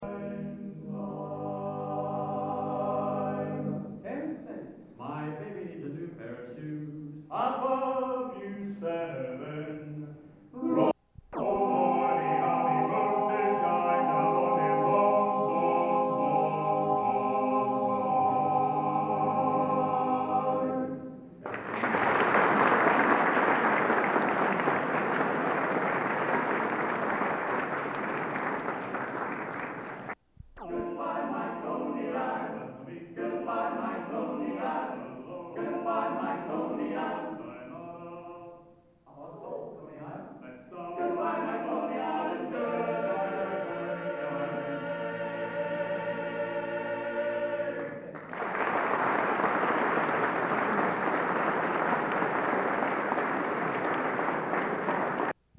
Location: Florence, Italy
Genre: Barbershop | Type:
64-Florence-Italy09--Barbershop-Tune.mp3